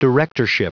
Prononciation du mot directorship en anglais (fichier audio)
directorship.wav